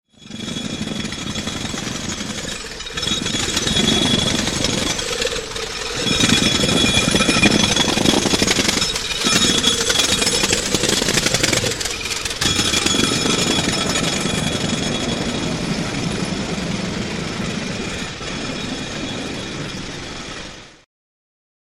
Draussen vor unserer Tür. Pressluftklavier mit Hall.